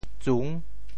How to say the words 捘 in Teochew？
捘 Radical and Phonetic Radical 扌 Total Number of Strokes 10 Number of Strokes 7 Mandarin Reading zùn TeoChew Phonetic TeoThew zung6 文 Chinese Definitions 捘zùn 1.推，挤。